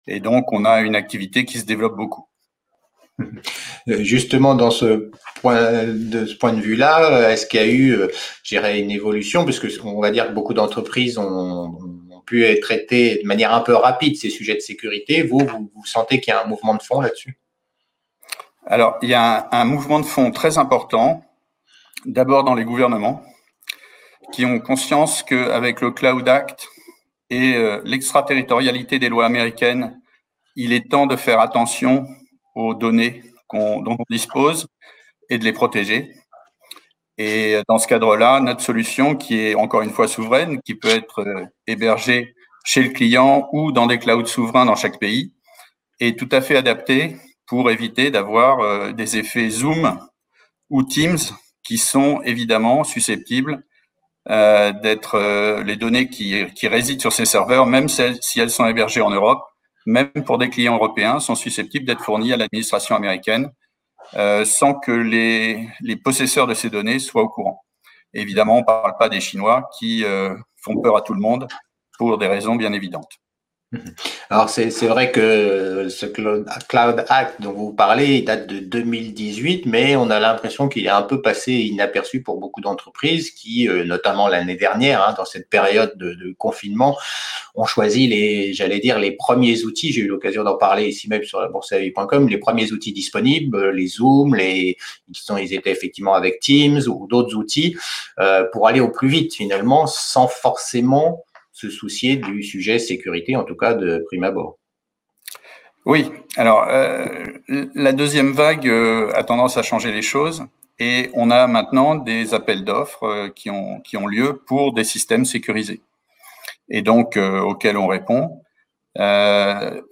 La société vous intéresse, toutes les interviews déjà diffusées sur la Web Tv sont ici :  Vidéos Streamwide